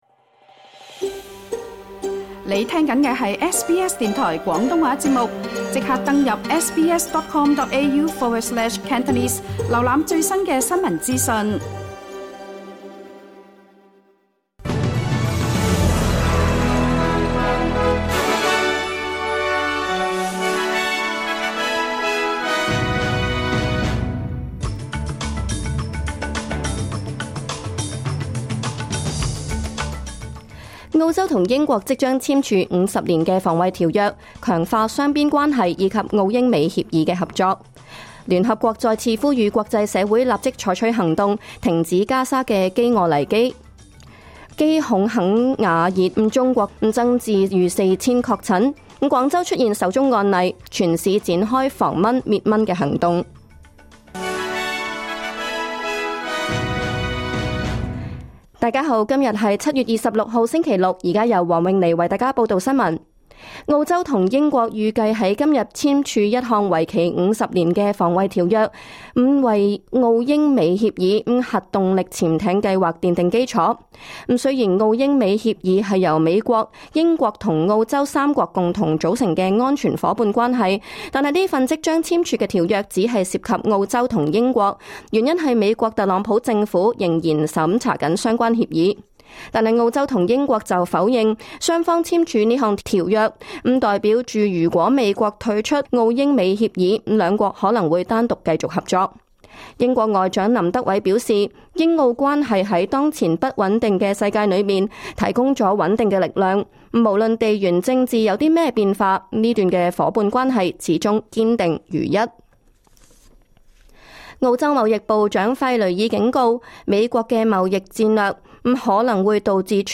2025 年 7 月 26 日 SBS 廣東話節目詳盡早晨新聞報道。